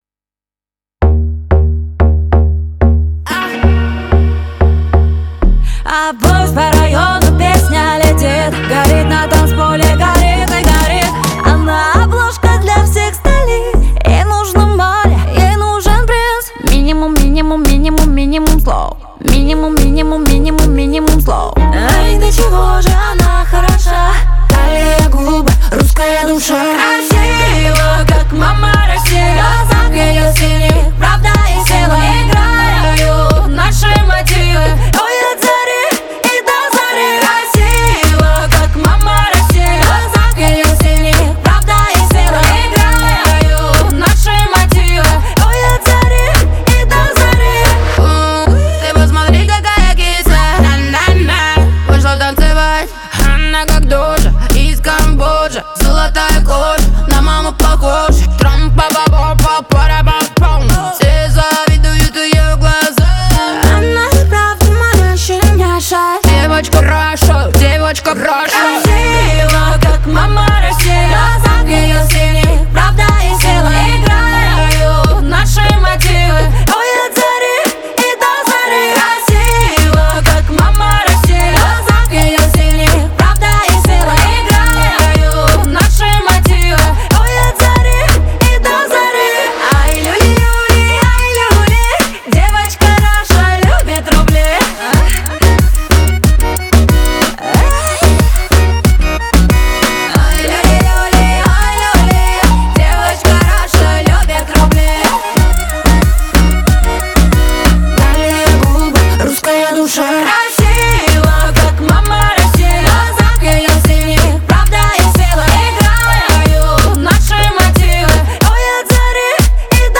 • Жанр: Песни про маму